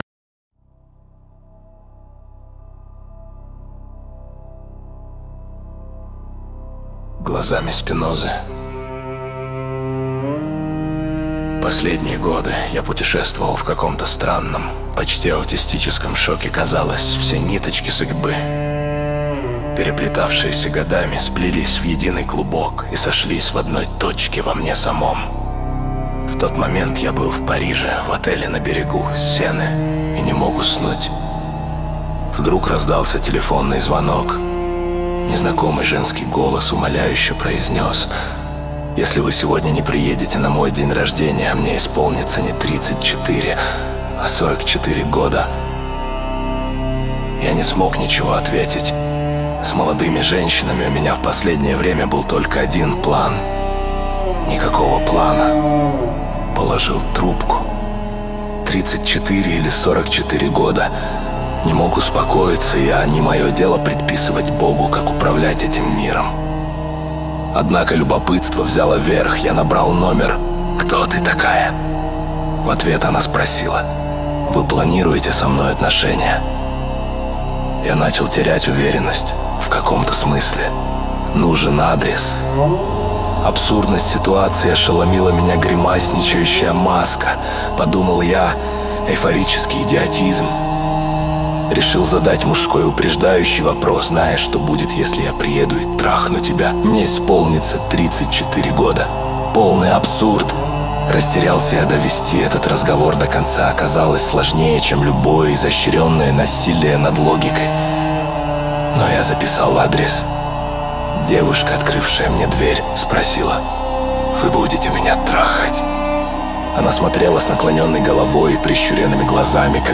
Глазами Спинозы. Аудио-нарратив.